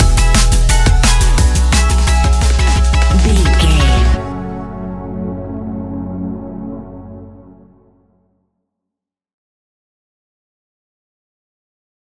Ionian/Major
F♯
electronic
techno
trance
synths
synthwave